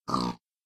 pig_say2.ogg